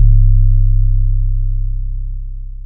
808 [what to doo].wav